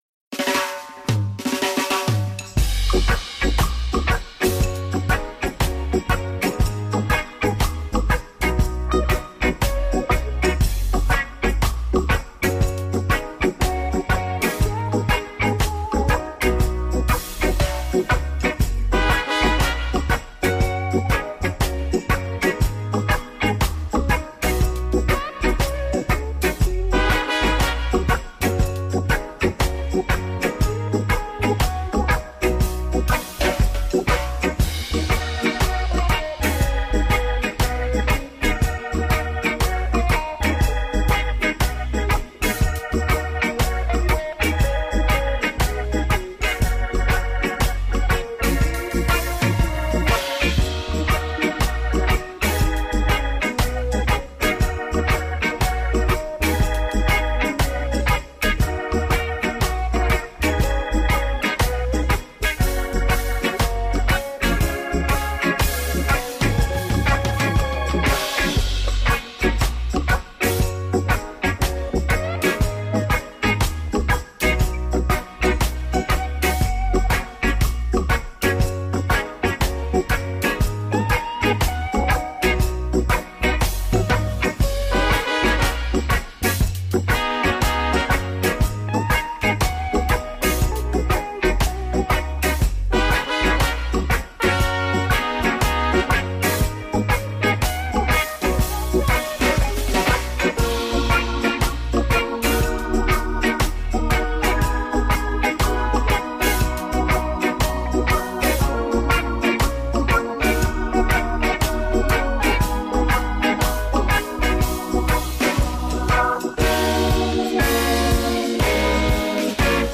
reggae-ska-playlist-1.mp3